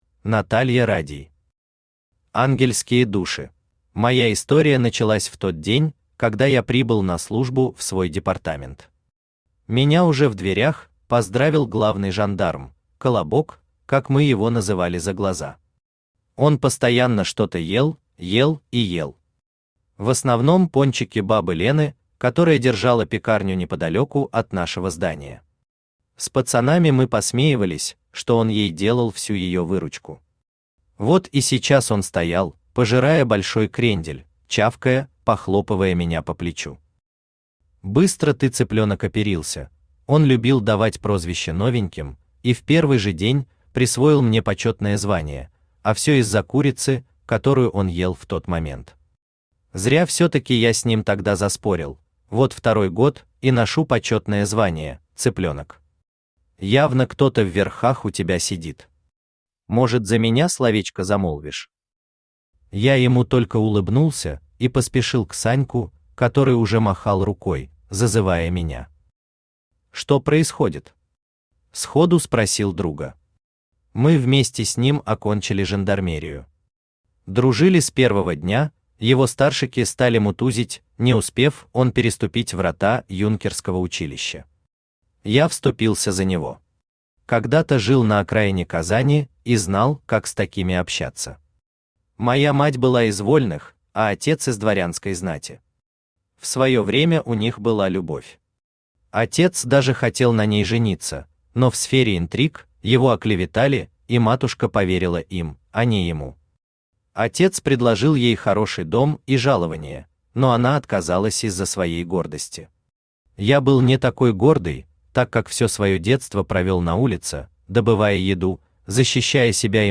Aудиокнига Ангельские души Автор Наталья Радий Читает аудиокнигу Авточтец ЛитРес.